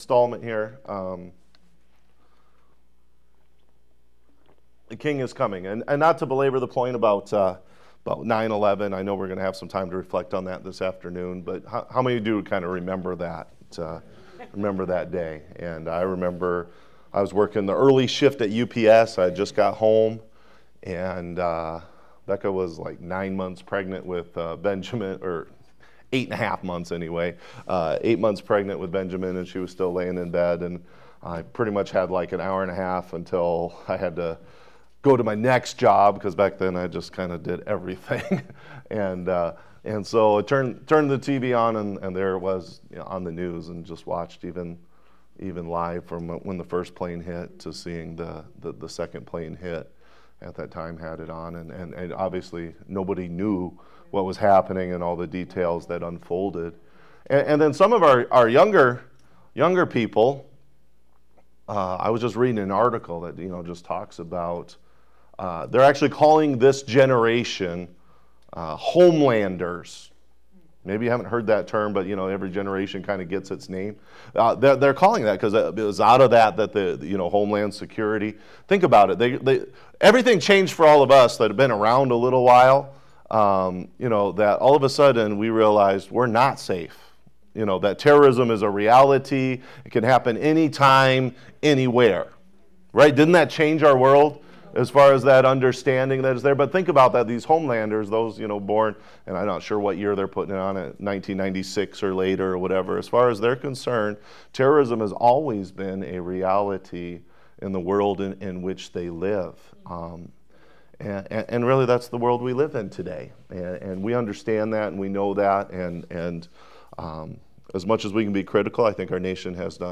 Sermon Archive - Life Worship Center